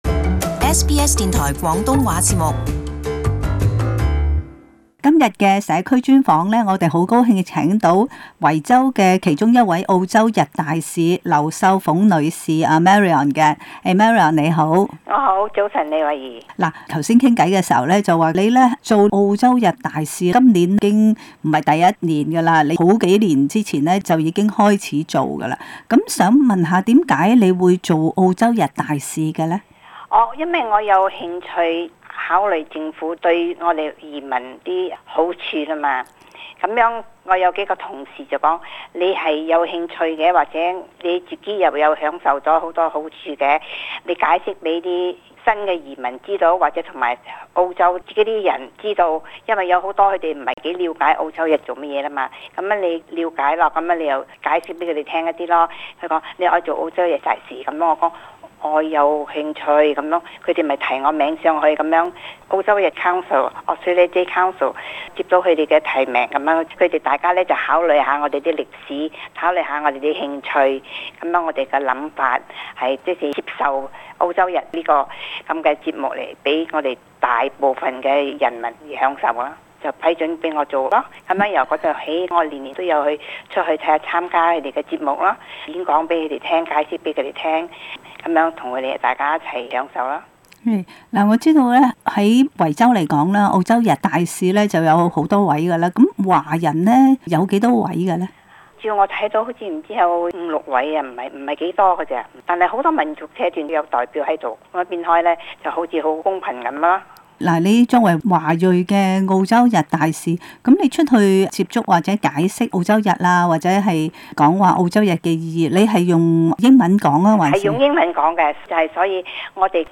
【社區專訪】澳洲日大使